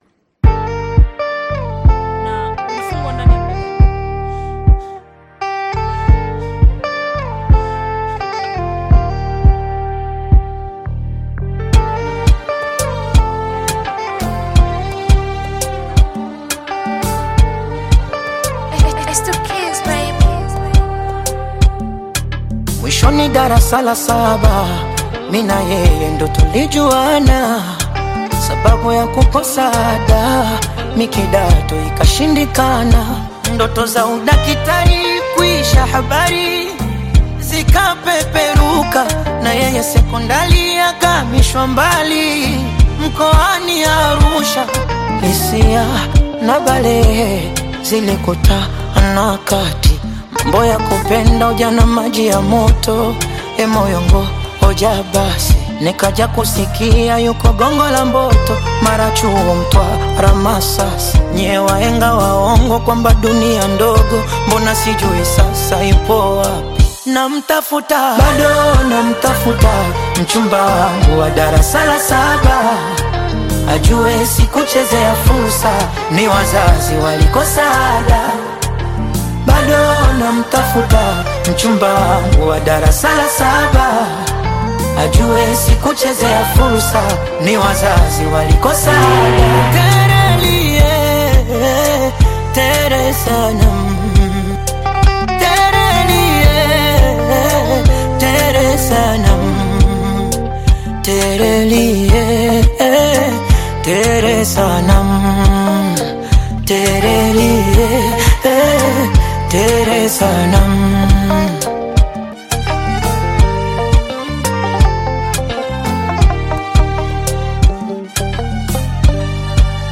is an emotional Afro-Pop/Bongo Flava single
soulful delivery